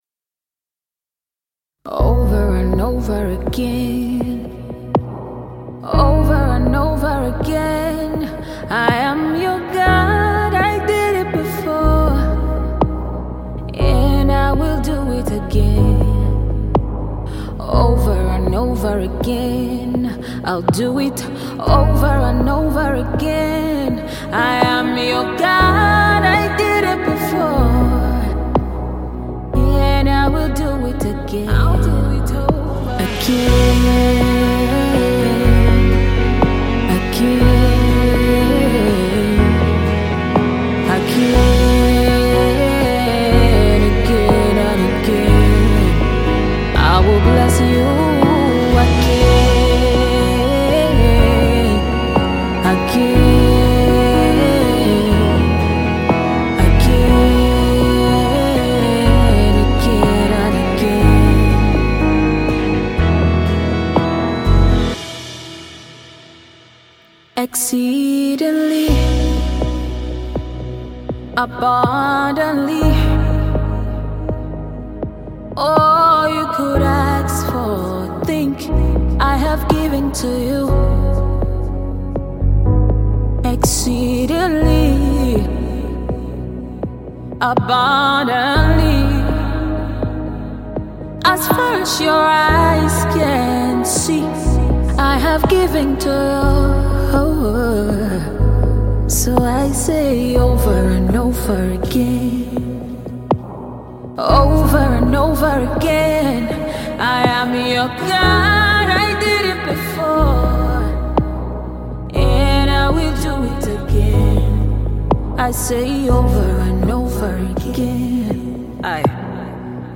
gospel single
spirit filled and soul lifting songs